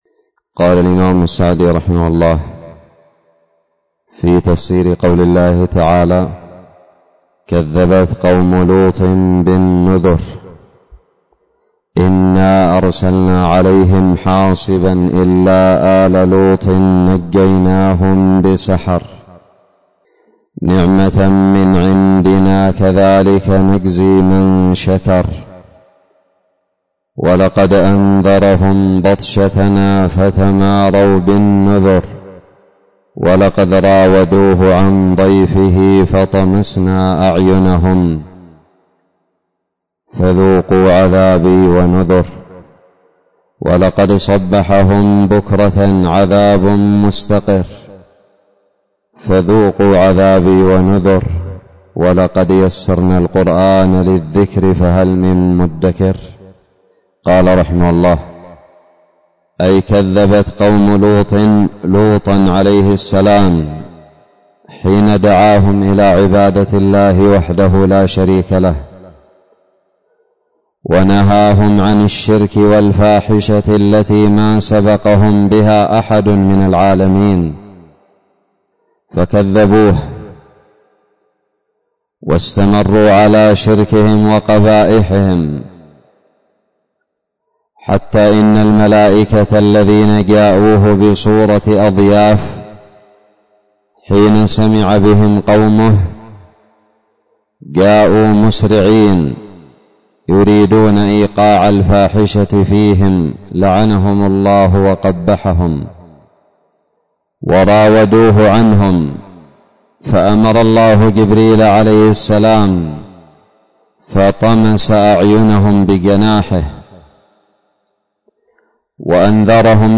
الدرس السادس من تفسير سورة القمر
ألقيت بدار الحديث السلفية للعلوم الشرعية بالضالع